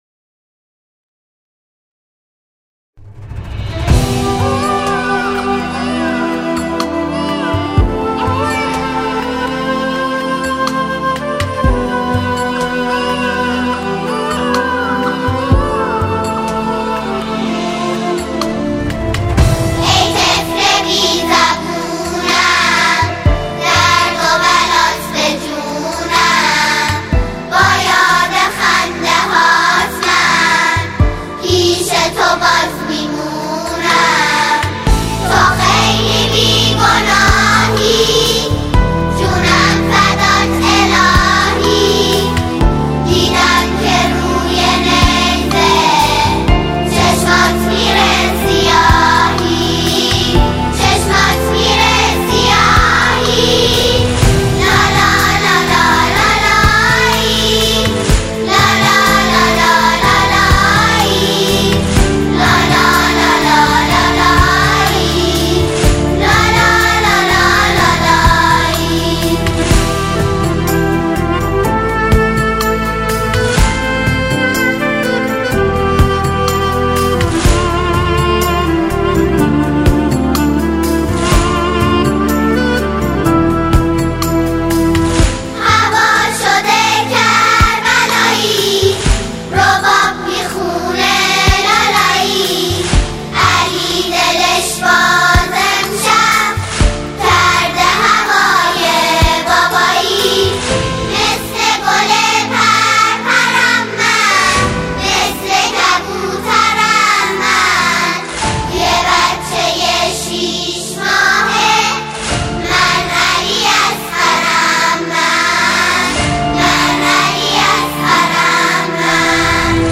سرودهای حضرت علی اصغر علیه السلام